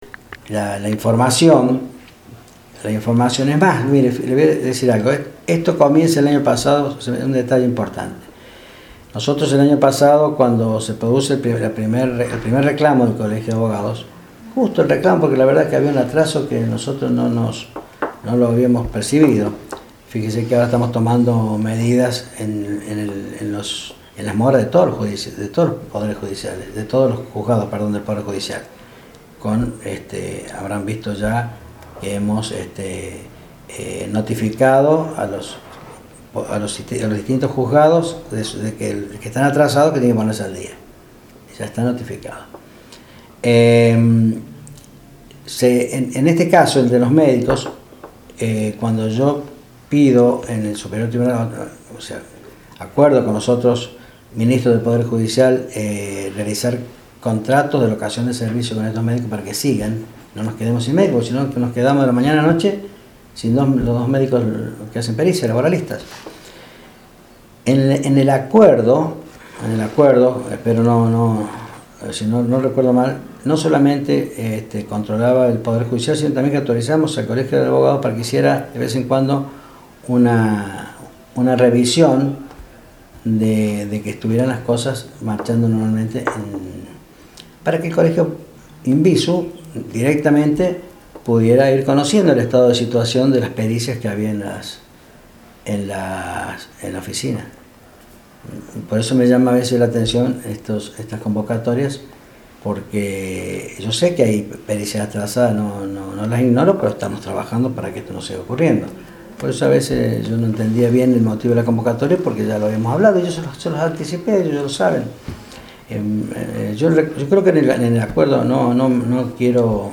En diálogo con medios de prensa de Villa Mercedes, el ministro del Superior Tribunal, Dr. Carlos Cobo informó que desde el año pasado se encuentran trabajando en pos de contar con las pericias al día.